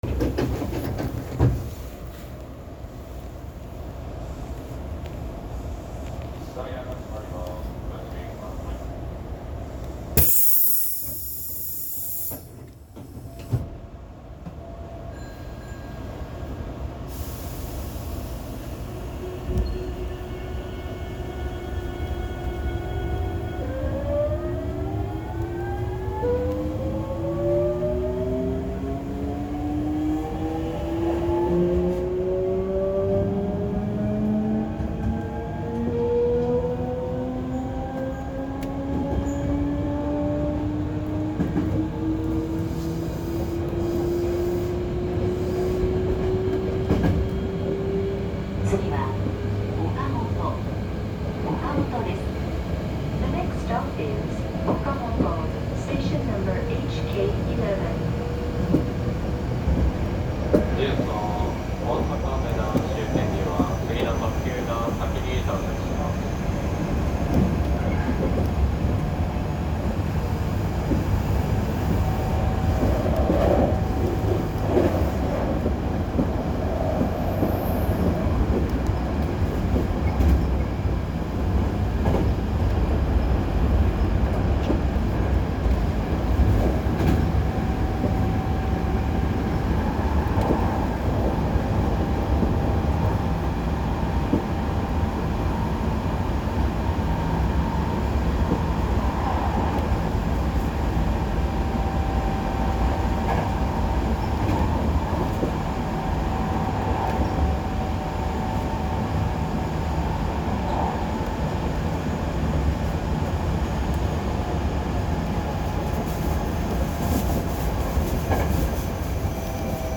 ・8000系走行音
【神戸本線】御影→岡本
同系列の8300系の走行音は何通りかあるのですが、8000系は東芝GTOとなります。東芝のGTOとしては聞き慣れた音ですが、今となってはこれも貴重です。